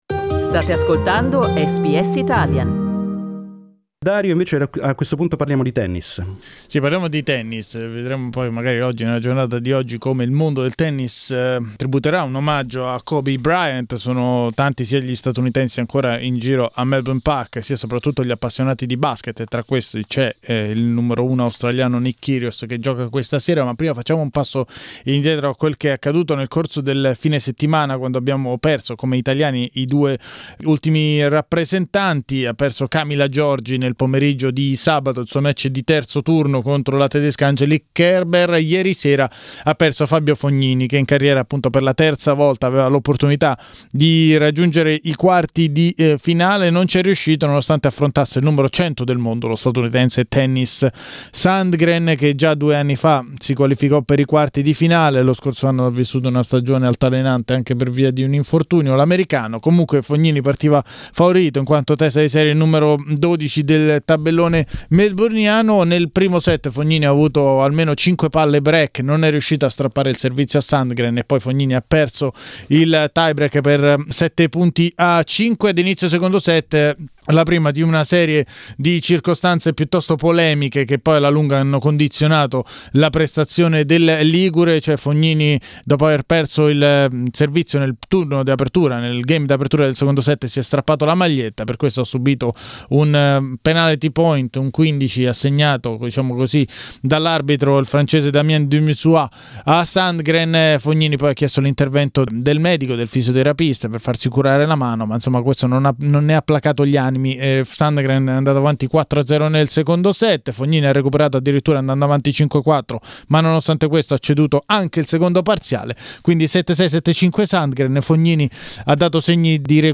Le parole dei protagonisti degli ottavi di finale degli Australian Open.